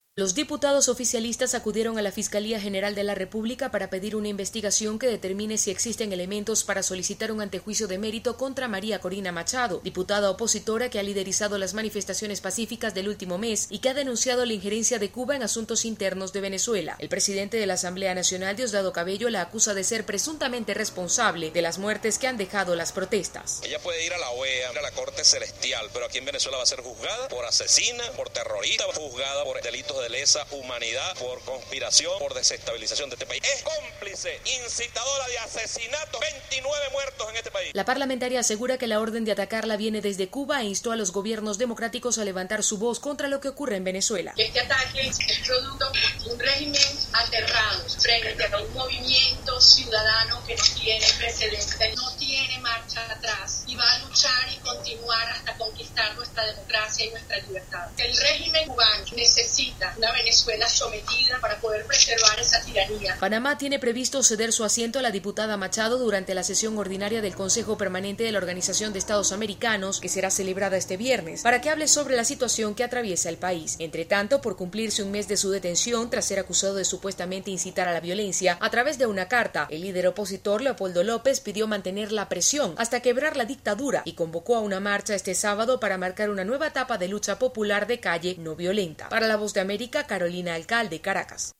En Venezuela diputados oficialistas piden a la fiscalía inicie investigación para acusar a Maria Corina Machado como autora de instigación y culpable de 29 cargos de asesinato entre otros. Tenemos el informe desde Venezuela con los detalles la corresponsal de la Voz de America